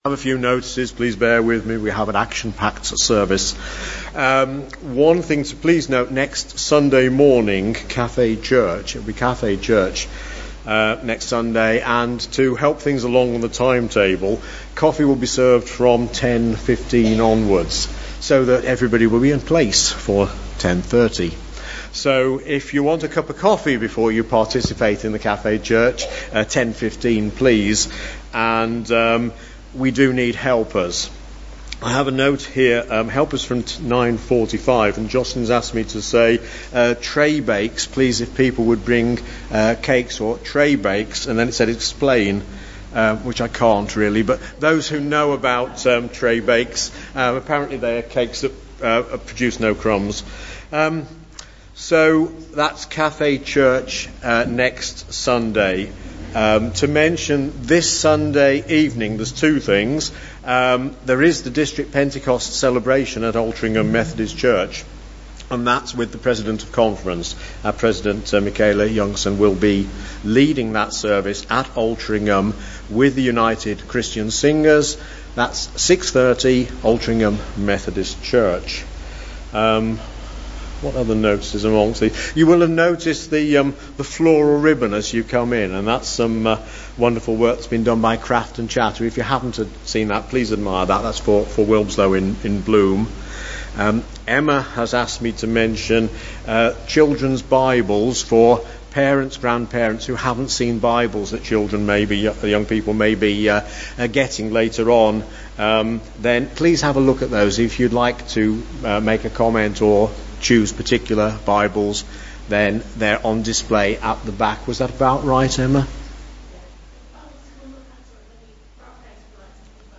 2019-06-09 Communion service
Genre: Speech.